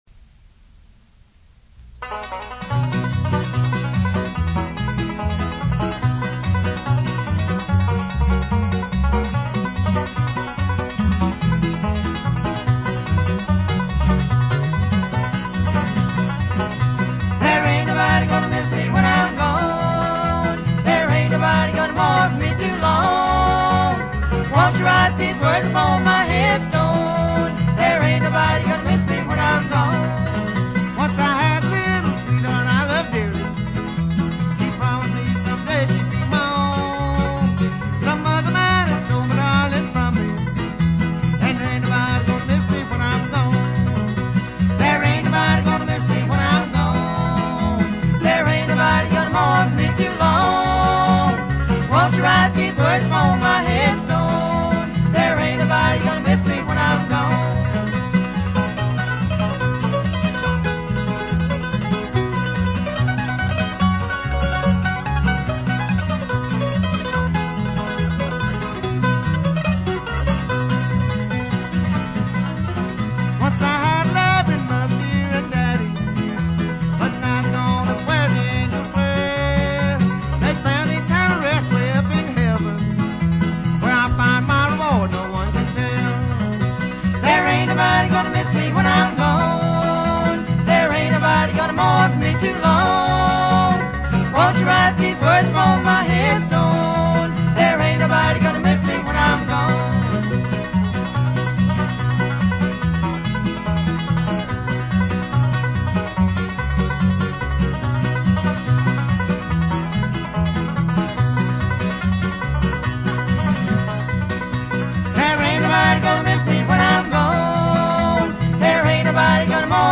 mandolin
banjo
guitar
upright bass.